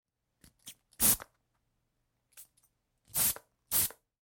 Шум пшикающего освежителя воздуха